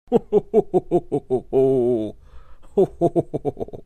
老人和蔼笑声音效_人物音效音效配乐_免费素材下载_提案神器
老人和蔼笑声音效免费音频素材下载